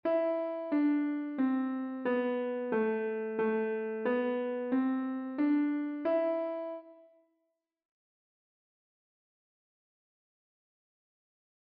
Use a slow steady beat, making even a short scale as beautiful as you can.
Click here to listen to the scale.